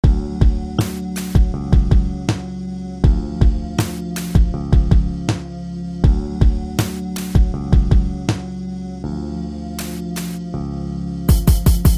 Tag: 80 bpm Hip Hop Loops Groove Loops 2.02 MB wav Key : Unknown